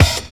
108 KIK+OP-R.wav